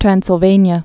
(trănsĭl-vānyə, -vānē-ə)